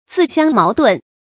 注音：ㄗㄧˋ ㄒㄧㄤ ㄇㄠˊ ㄉㄨㄣˋ
自相矛盾的讀法